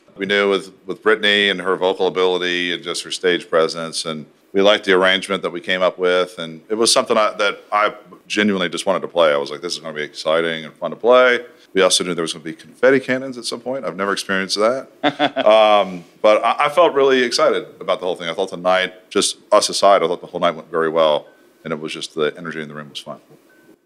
Audio / Brothers Osborne's TJ Osborne talks about closing the ACM Awards with Brittney Spencer.